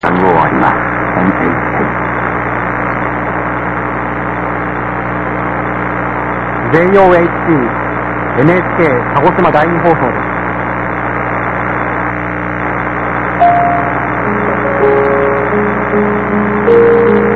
The announcements are pre-recorded and may be read by either a male or a female announcer.
The NHK2 on 1386 in Kagoshima (MP3), also by a male announcer:
"JOHC NHK Ka-go-she-ma---Die-knee---Hoe-so---des"